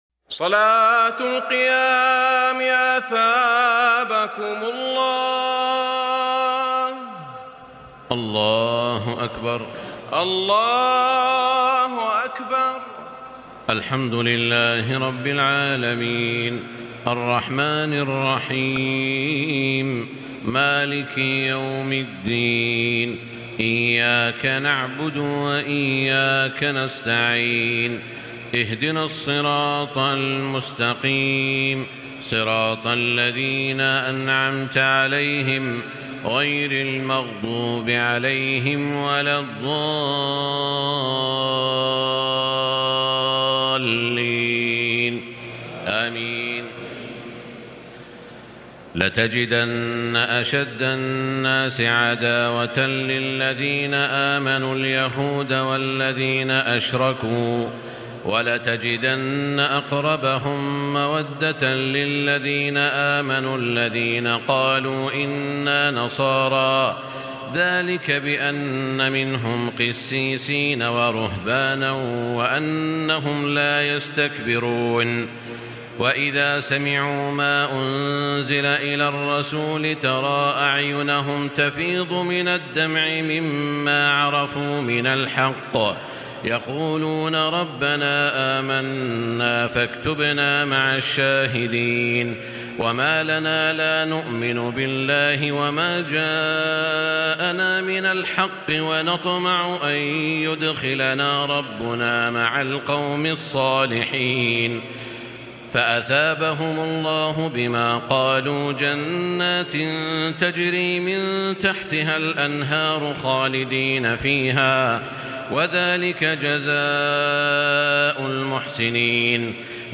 تهجد ليلة 27 رمضان 1440هـ من سورتي المائدة (82-120) و الأنعام (1-58) Tahajjud 27 st night Ramadan 1440H from Surah AlMa'idah and Al-An’aam > تراويح الحرم المكي عام 1440 🕋 > التراويح - تلاوات الحرمين